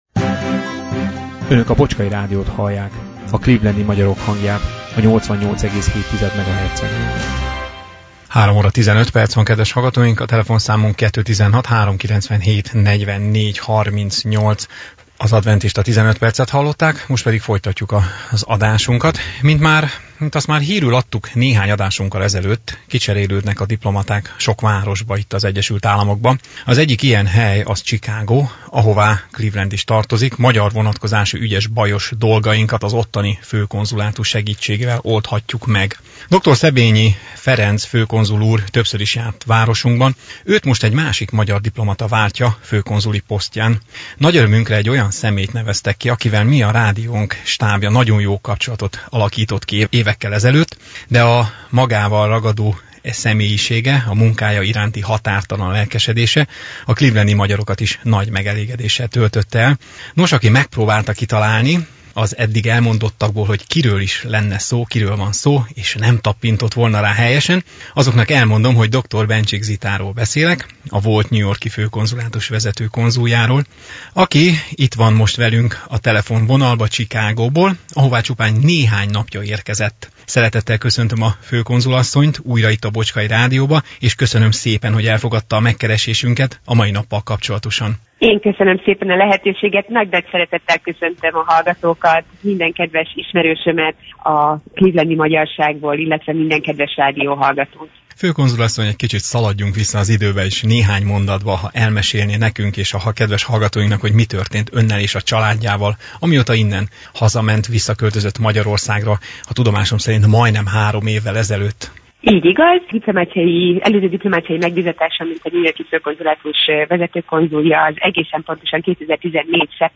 Dr. Bencsik Zita Magyarország chicagói főkonzulátusának frissen kinevezett főkonzuljával beszélgettünk élőben az április 9-ei élő adásunkban, aki csupán pár napja érkezett az Egyesült Államokba.